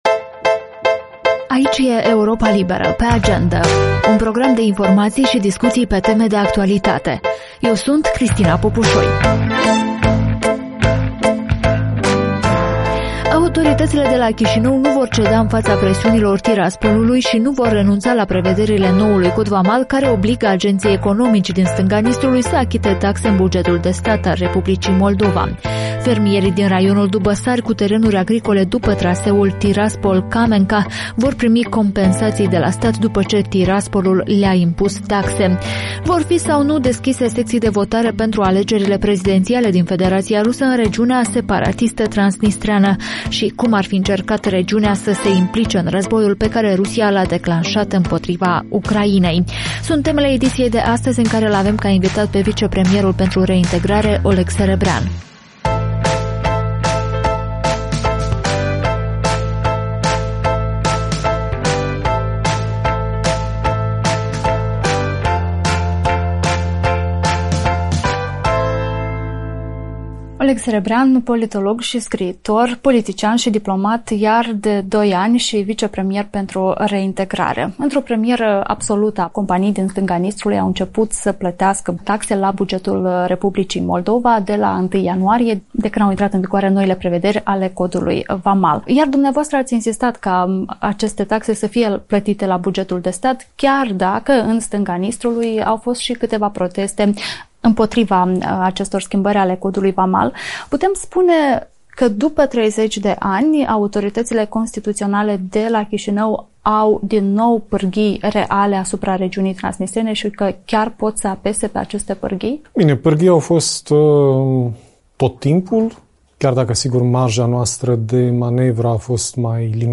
Vicepremierul pentru Reintegrare Oleg Serebrian vorbește în podcastul video „Pe Agendă” de la Europa Liberă despre cum va soluționa problema fermierilor cu terenuri după traseul Tiraspol-Camenca, și de ce Chișinăul va continua să pună în practică noul cod vamal și „nu va ceda” în fața Tiraspolului.